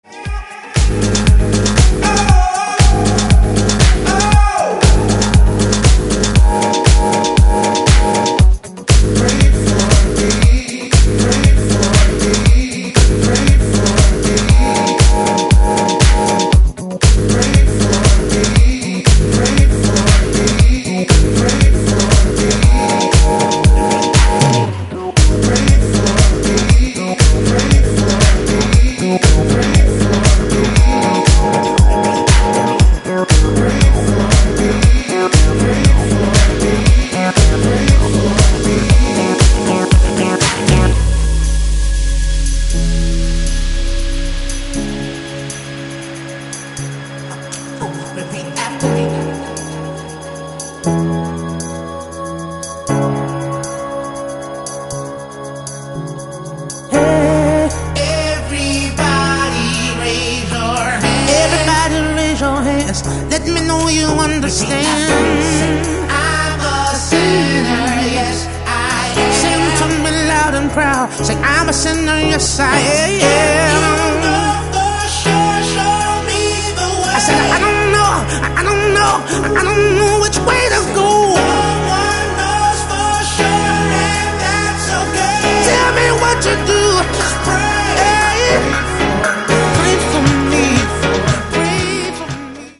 ブギーでソウルフルなディスコ、ファンク、ハウス、を散りばめたグレイト過ぎる好内容です！